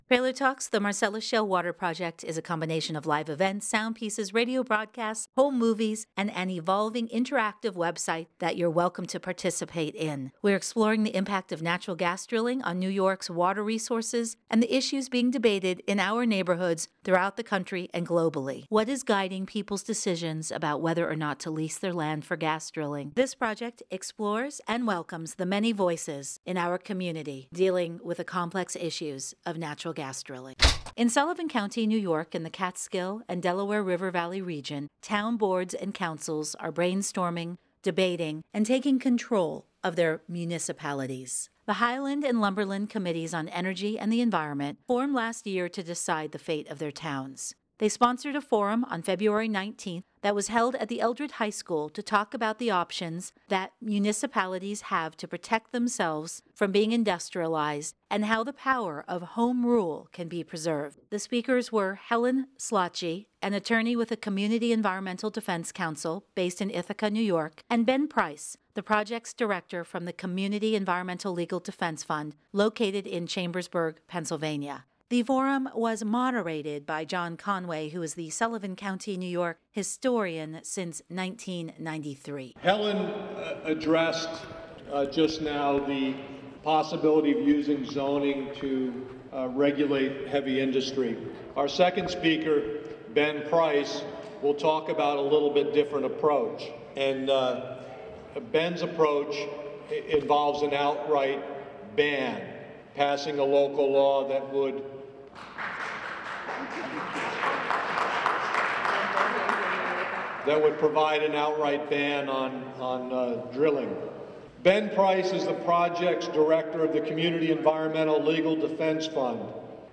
They sponsored a forum on February 19th that was held at the Eldred High School to talk about the options that municipalities have to protect themselves from being industrialized and how the power of Home Rule can be preserved.